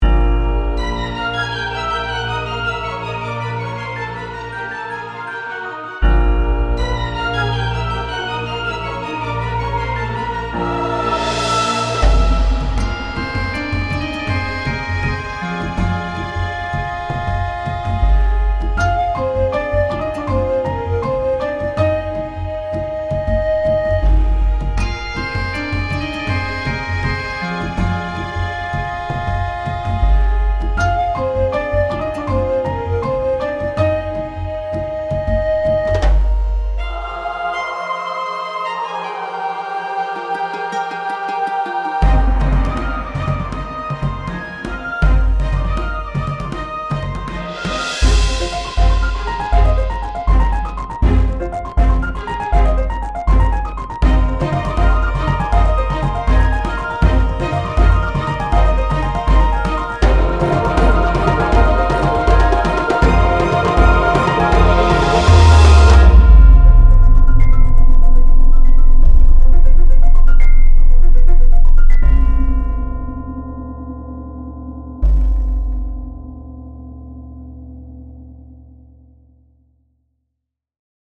古代文明を感じさせるような楽曲です。